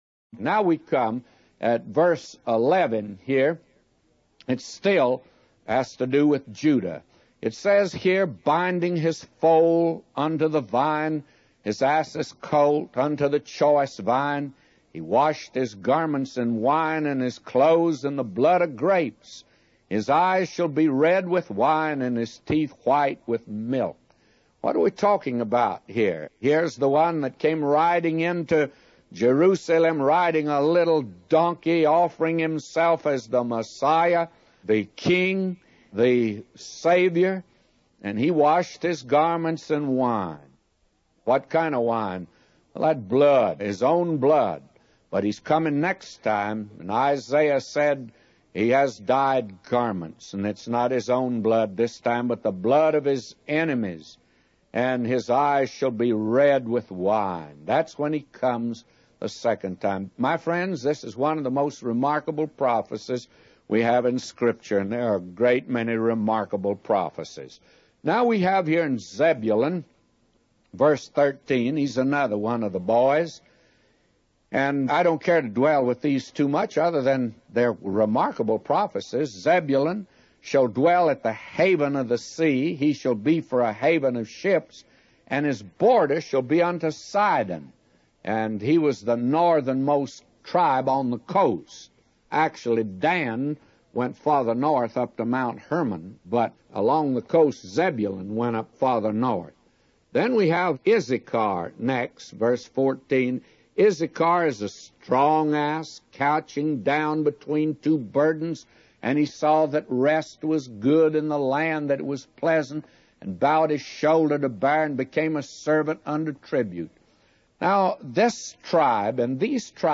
A Commentary By J Vernon MCgee For Genesis 49:11-999